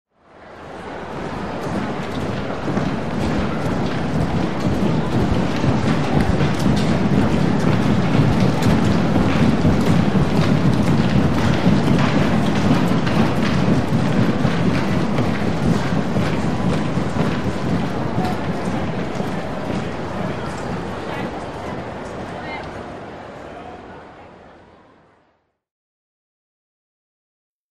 Players, Heavy Walking By In Tunnel, Room Reverb. Various Sports, Players Approaching, Prior To Game.